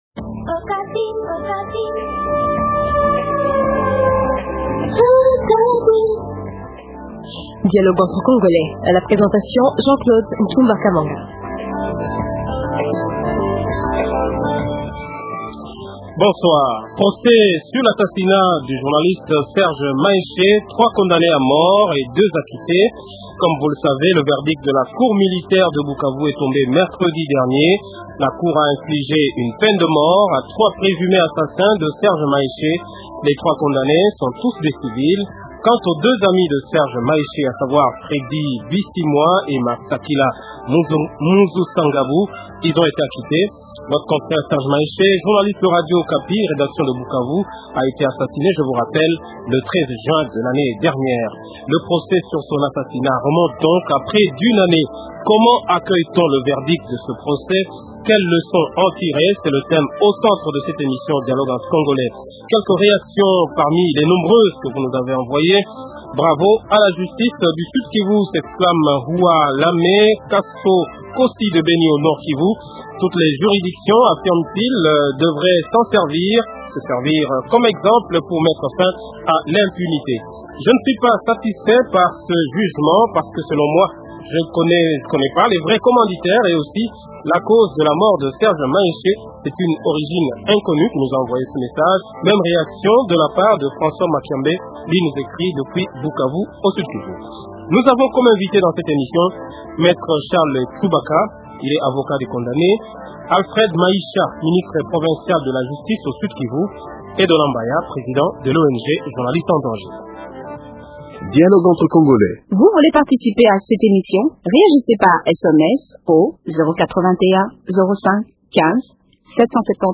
Alfred Mahisha ,ministre provincial de la justice au Sud kivu